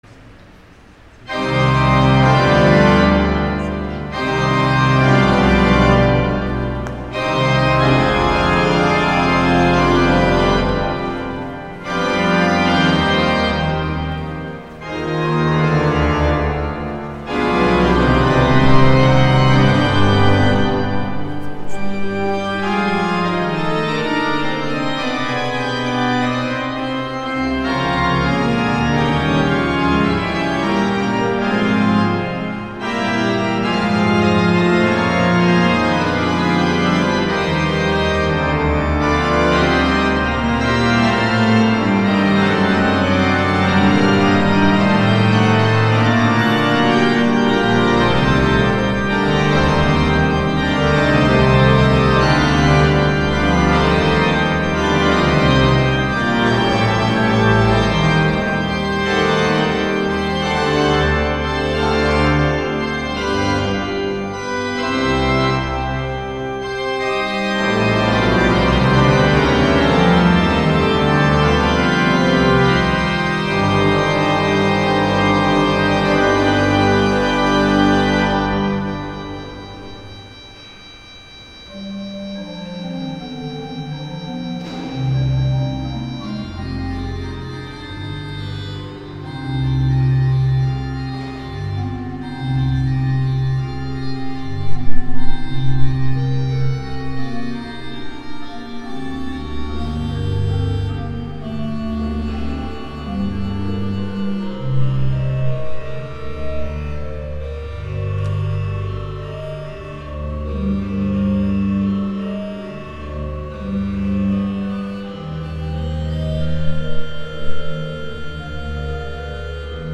Órgano del Sol Mayor